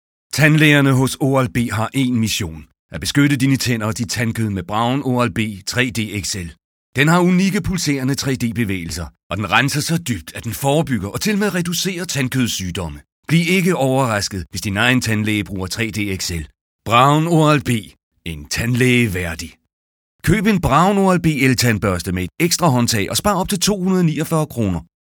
Sprecher dänisch, mature, convincing, calm, harmonic
Sprechprobe: eLearning (Muttersprache):
Danish voice over talent, mature,convincing, calm, harmonic